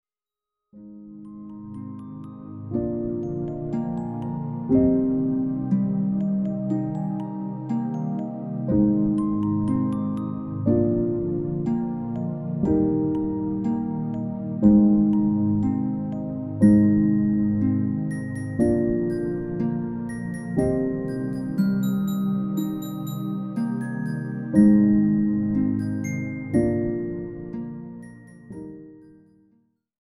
Lullaby covers